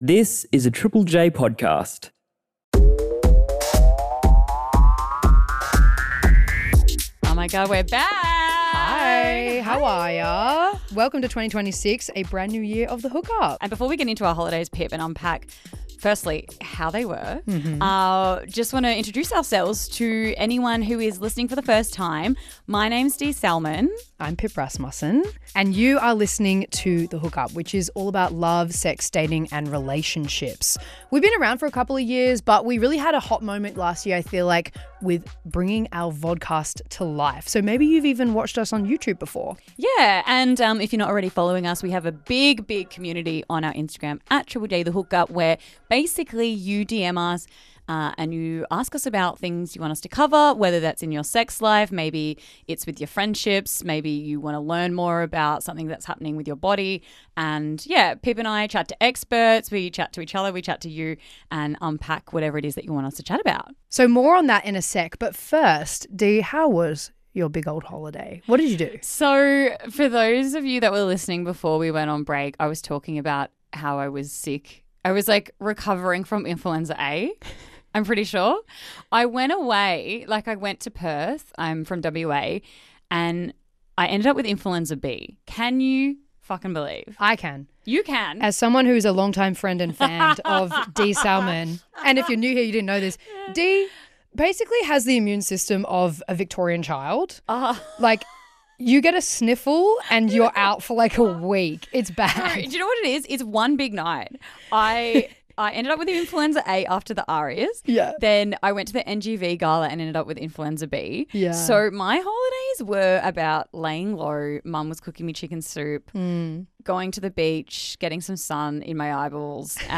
The Hook Up is an ABC podcast, produced by triple j. It is recorded on the lands of the Wurundjeri people of the Kulin nation.